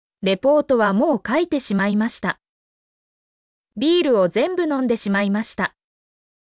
mp3-output-ttsfreedotcom-54_vlZ5l0Cm.mp3